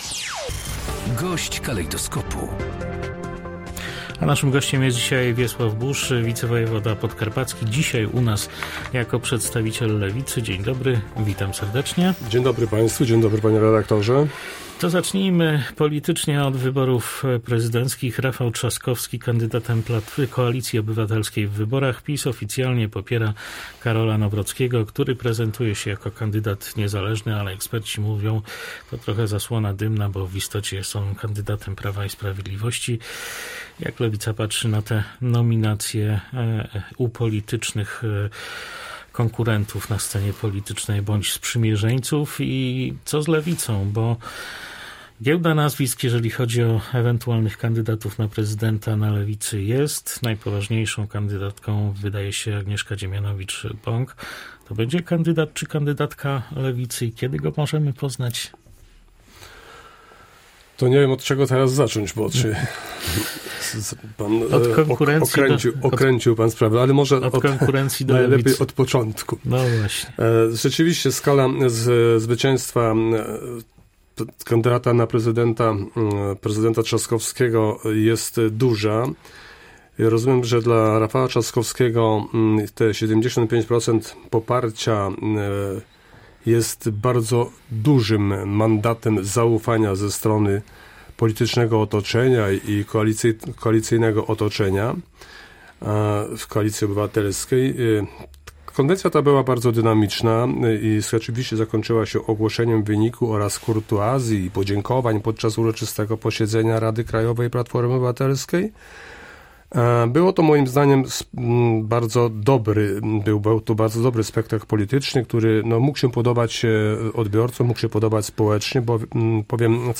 -Nowa Lewica postanowiła, że wystawi kandydata na prezydenta RP. Ogłosimy go w połowie grudnia, bądź na przełomie roku – powiedział na naszej antenie Wiesław Buż (Lewica), wicewojewoda podkarpacki.